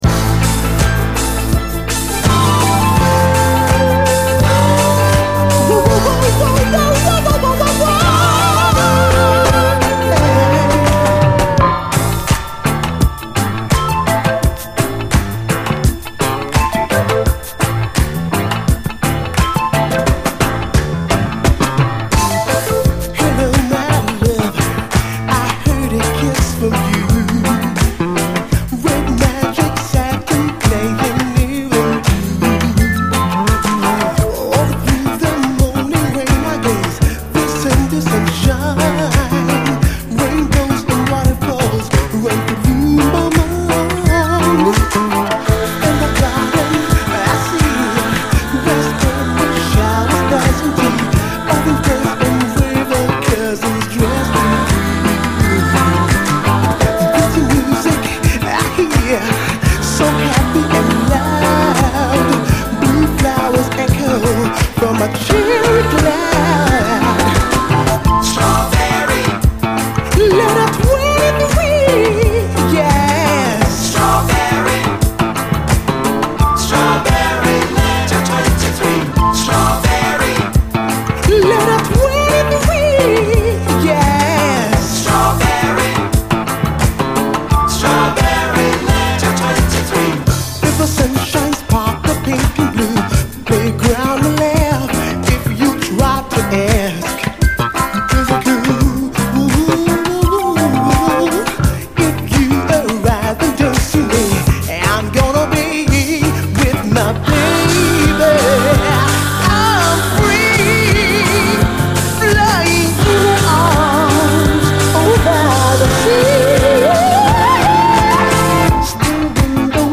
A面中頃にプチノイズ４回程あり。試聴ファイルはこの盤からの録音です。
抜群にキャッチーなイントロ、そしてエレピ＆ピアノが演出するエレガンス！